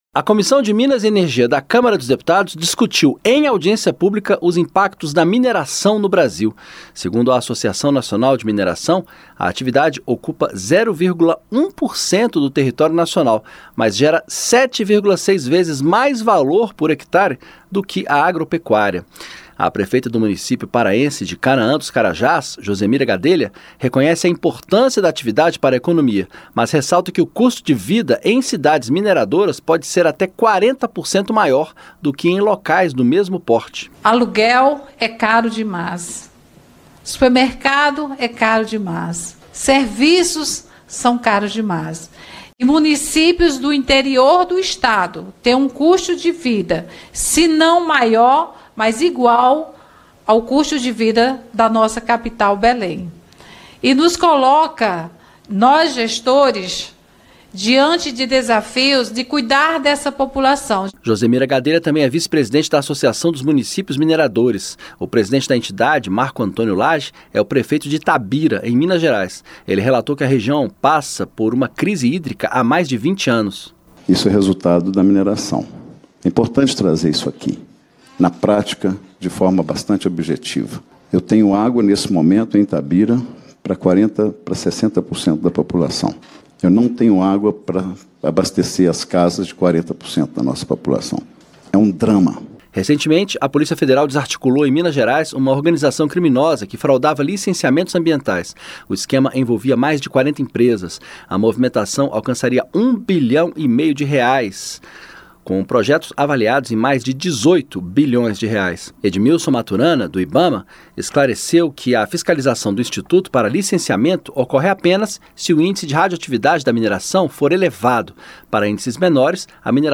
REPRESENTANTES DE CIDADES MINERADORAS FALAM SOBRE IMPACTOS POSITIVOS E NEGATIVOS DA ATIVIDADE EM AUDIÊNCIA NA CÂMARA.